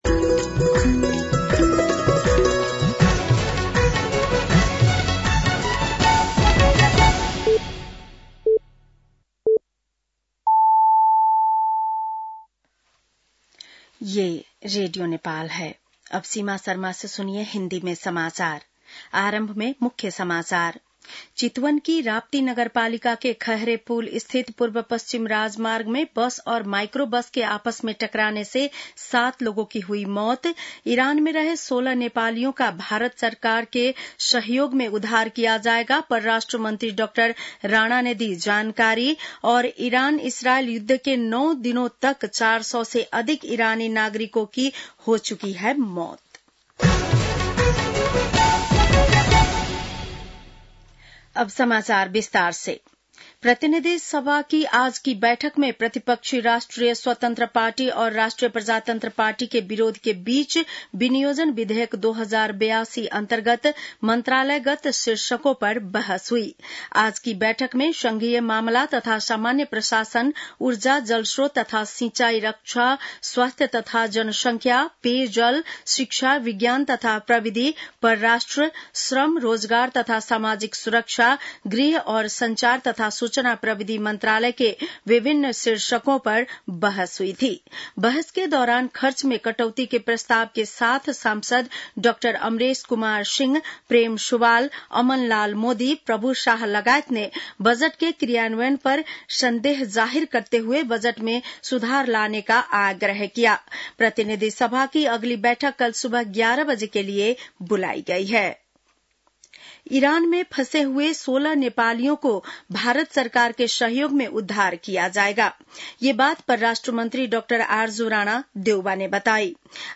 बेलुकी १० बजेको हिन्दी समाचार : ७ असार , २०८२